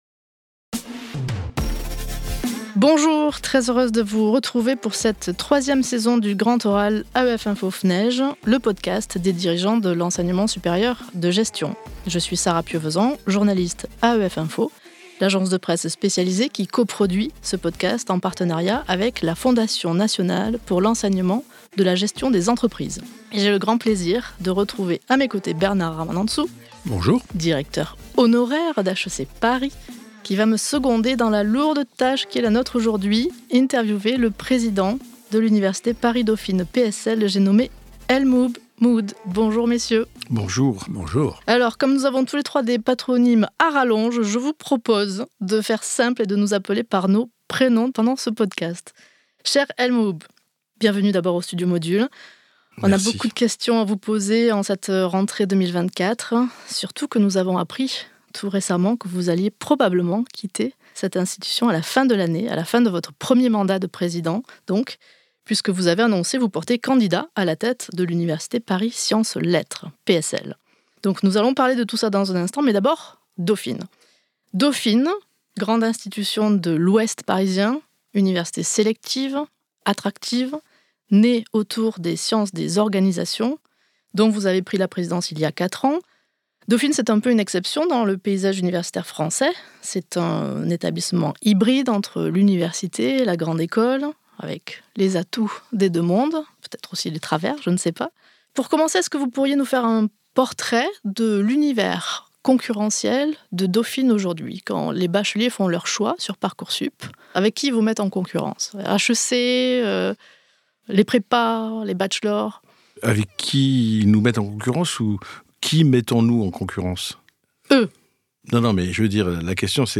Pour lancer cette 3e saison du "Grand Oral", le podcast co-produit par AEF info et la Fnege, El Mouhoub Mouhoud, président de l’université Dauphine-PSL, seul candidat déclaré à la présidence de l’université PSL – dont il exerce aussi la présidence par intérim – se plie à l’exercice du question-réponse. Paysage concurrentiel de Dauphine, disparition de la marque dans les classements, recrutement de doctorants, budget, articulation avec PSL… Un échange en forme de bilan de ses quatre années à la présidence de Dauphine.